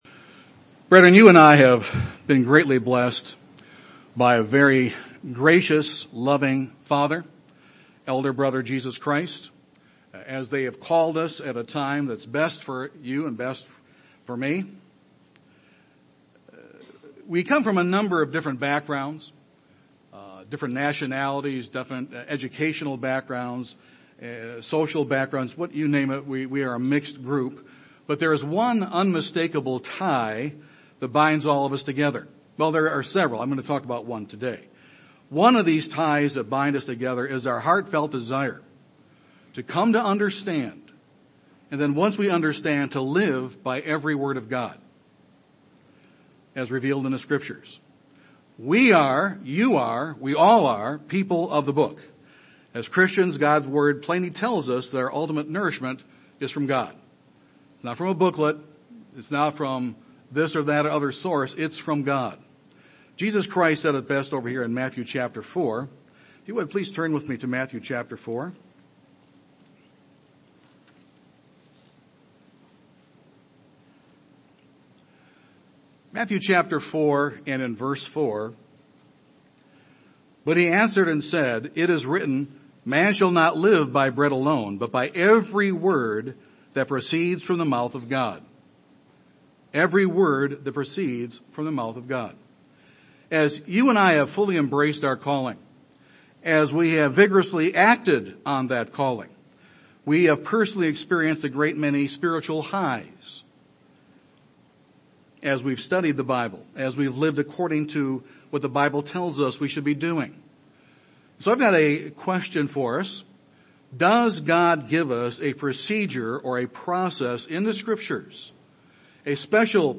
God has given us a procedure, a process for spiritual growth and change. This sermon will examine the mechanics of that process.